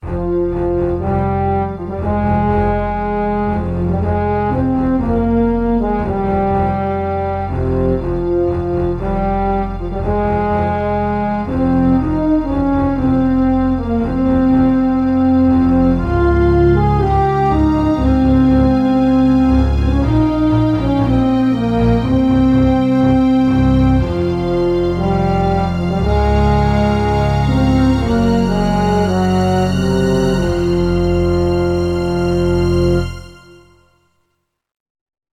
(gamerip)